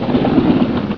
doorSlide.wav